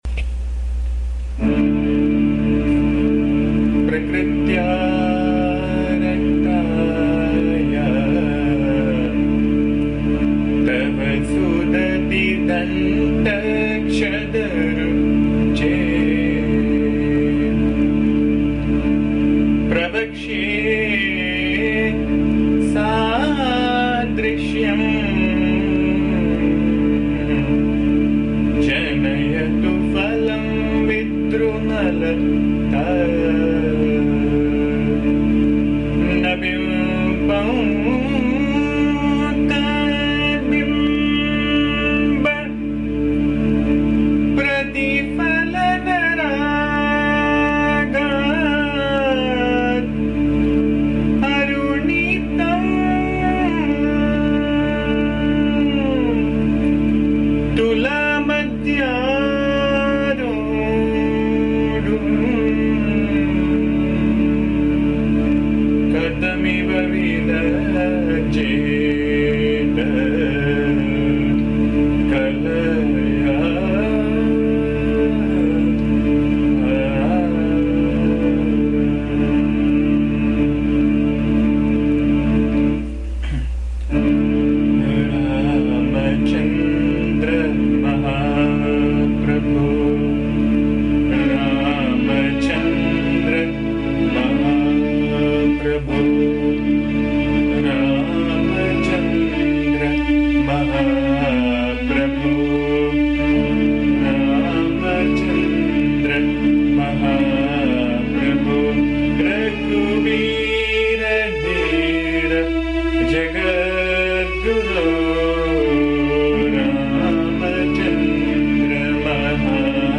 This song praises Rama who is considered as an avataara of Vishnu. The song is set in Kalyaani (Yaman Kalyaani) Raag and has simple words.
Please bear the noise, disturbance and awful singing as am not a singer.
AMMA's bhajan song